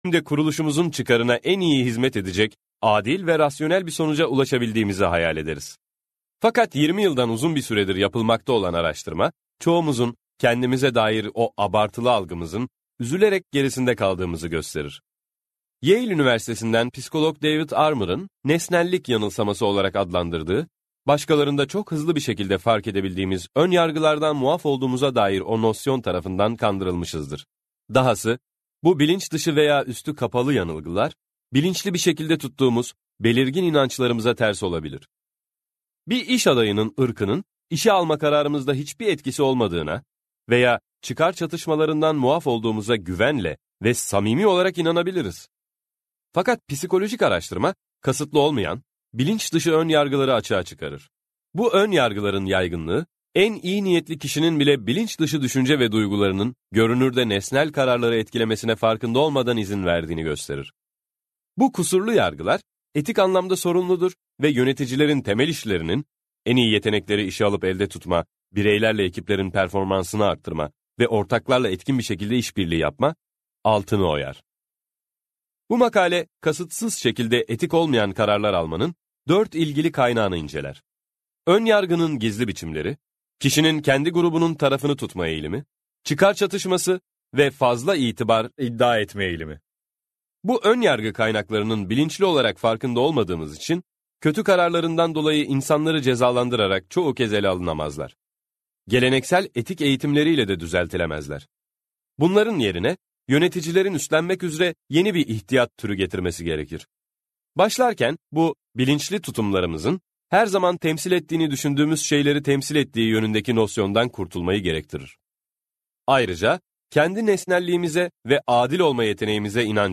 - Seslenen Kitap